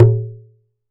West MetroPerc (46).wav